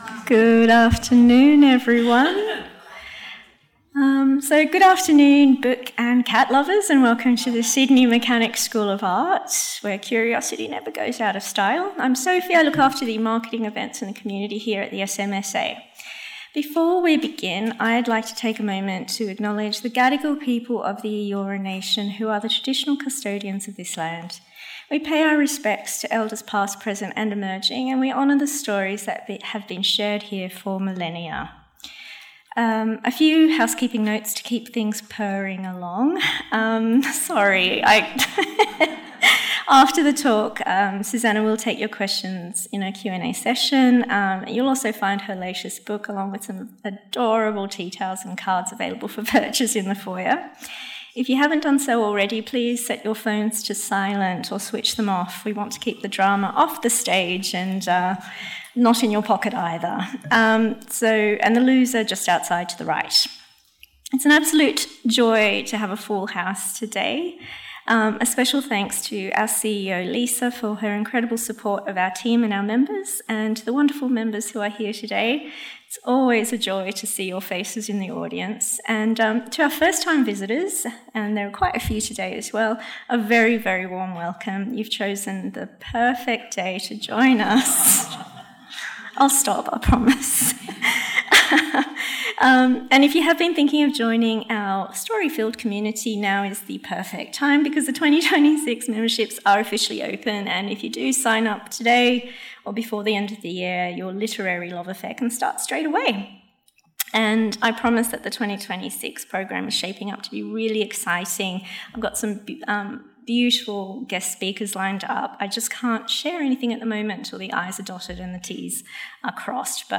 One utterly unmissable lunchtime event.